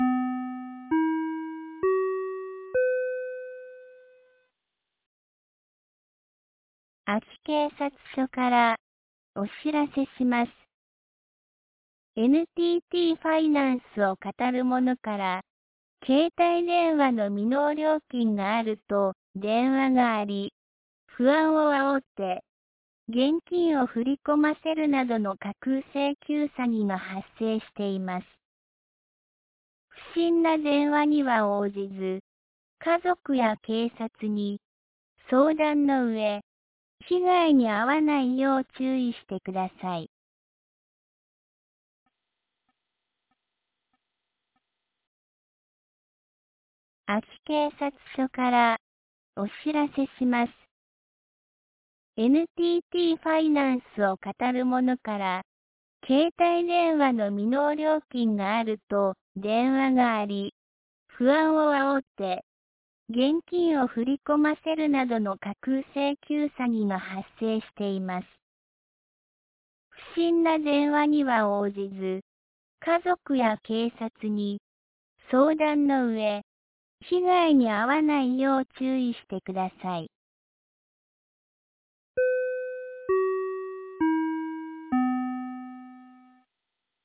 2025年02月20日 17時11分に、安芸市より全地区へ放送がありました。